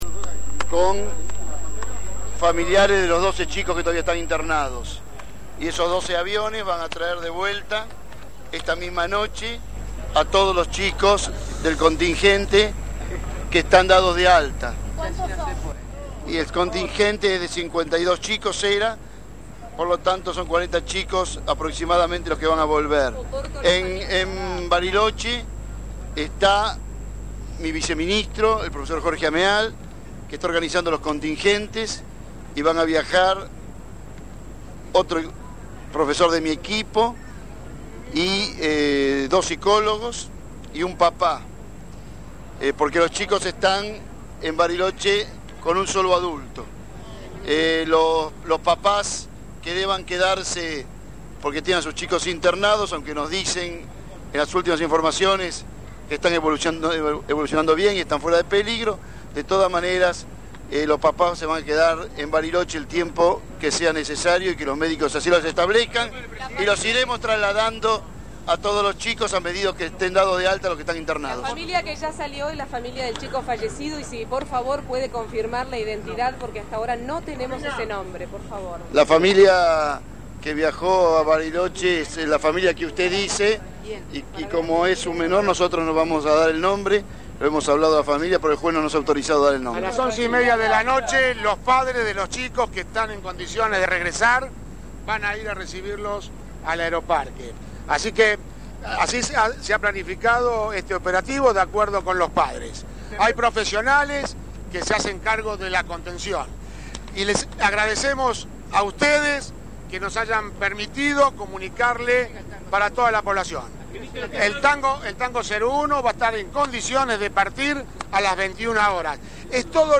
Audio: Declarariones de Mario oporto y Osvaldo Amieiro